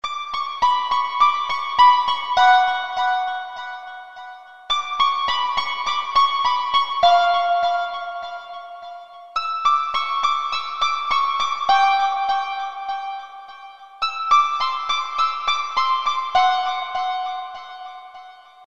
мелодия звучит так:
До си ля си . до си ля си. ми
До си ля си. до си ля си Ре диез.